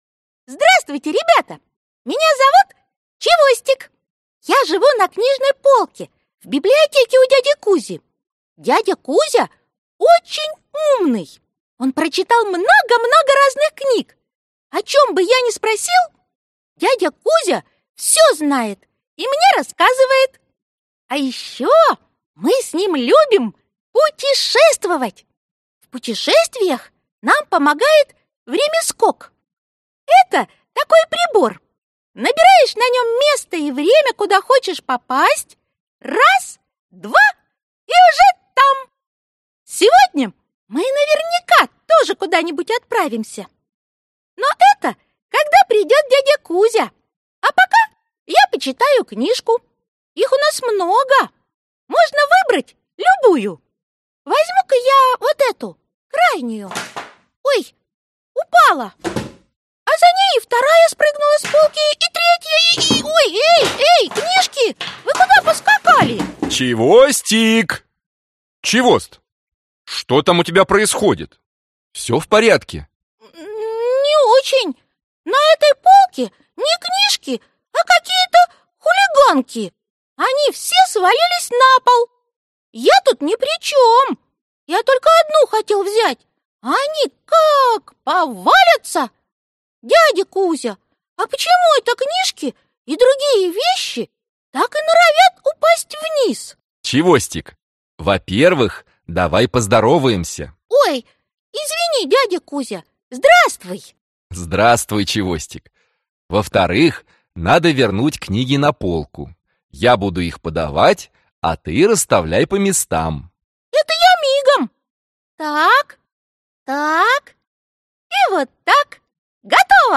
Аудиокнига Увлекательная физика | Библиотека аудиокниг